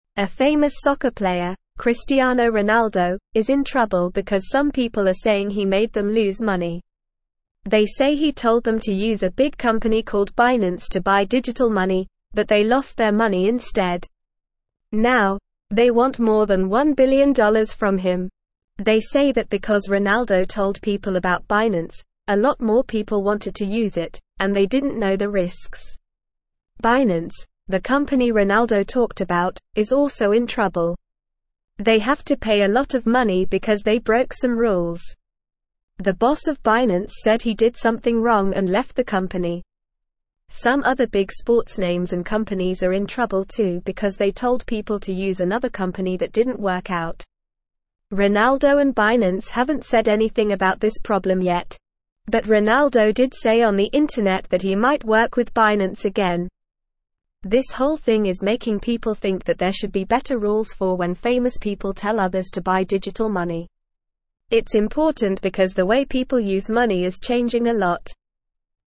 Normal
English-Newsroom-Lower-Intermediate-NORMAL-Reading-Cristiano-Ronaldo-in-trouble-for-promoting-bad-website.mp3